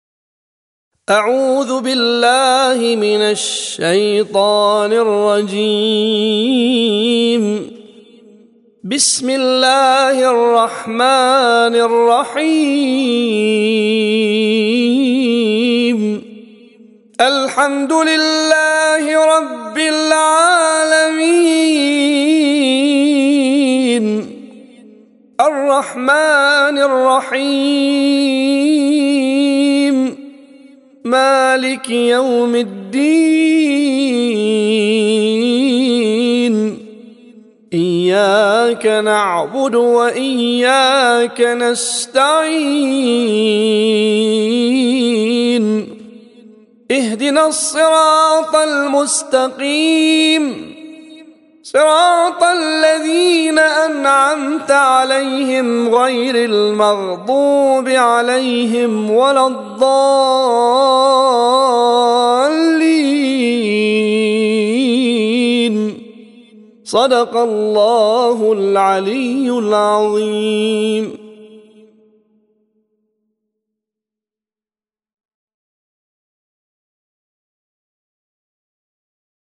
طور عراقي